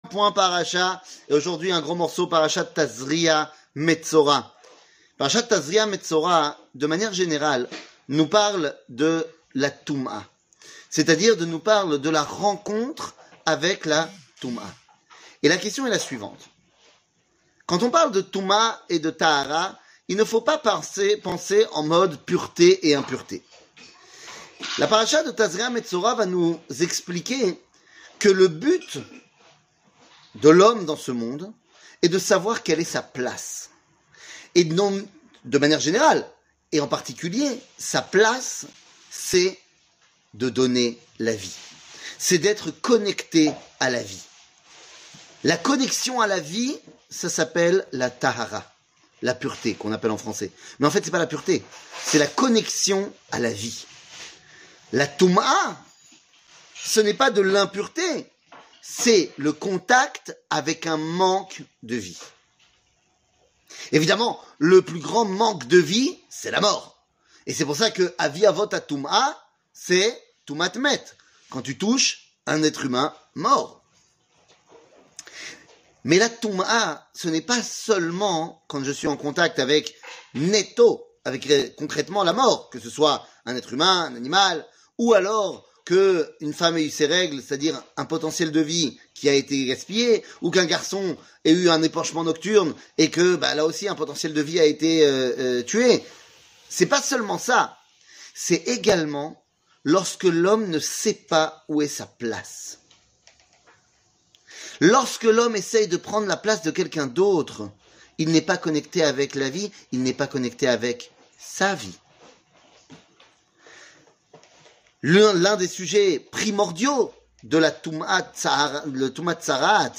שיעור מ 20 אפריל 2023
שיעורים קצרים